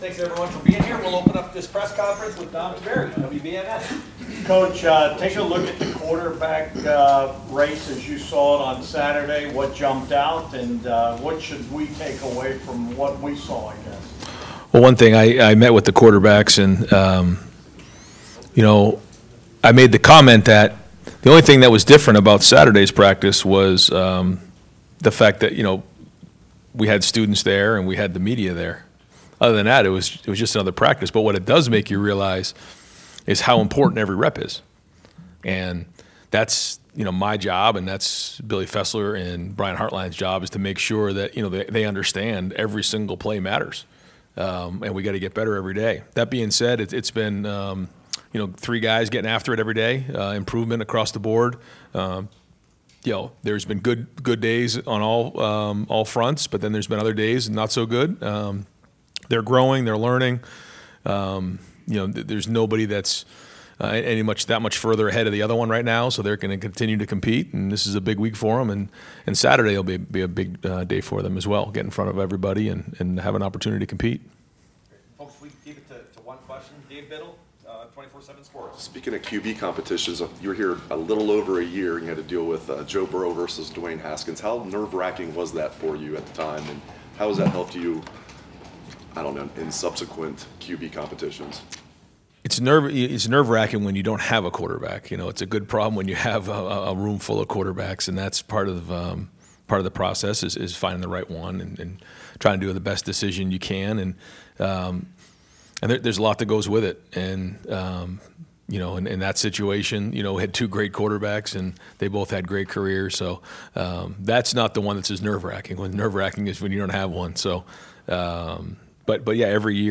Ohio State football coach Ryan Day dissects Spring Football with Media